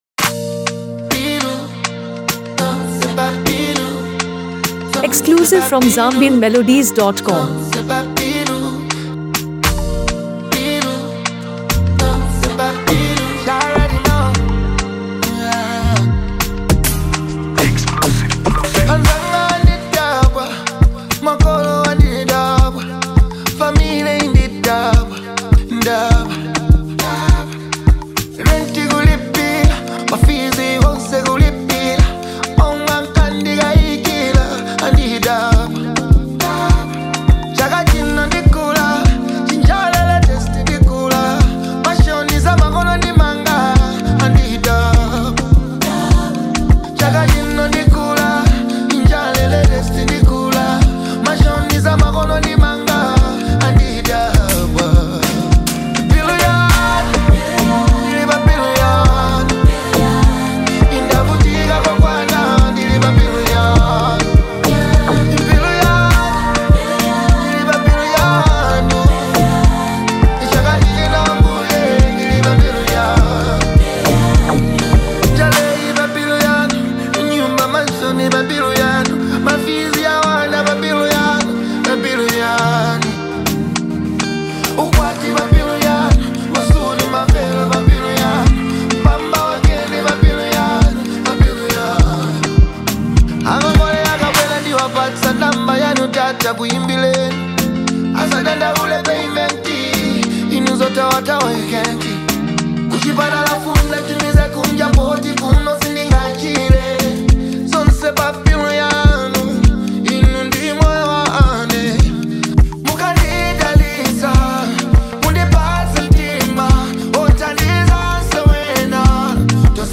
one of Malawi’s most celebrated R&B and Afro-pop artists
Known for his smooth vocals
With relatable lyrics and a catchy chorus
Many praise its infectious beat, sing-along hook